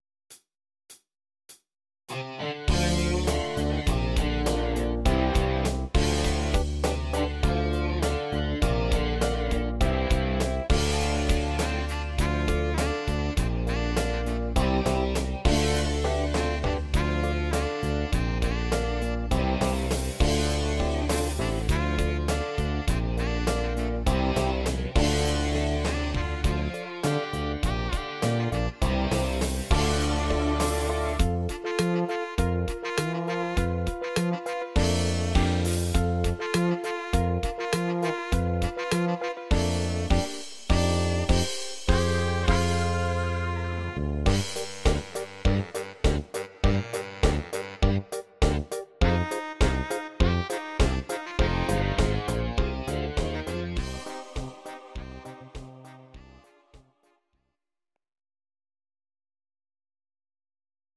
Audio Recordings based on Midi-files
Pop, Rock, 1970s